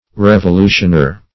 Search Result for " revolutioner" : The Collaborative International Dictionary of English v.0.48: Revolutioner \Rev`o*lu"tion*er\, n. One who is engaged in effecting a revolution; a revolutionist.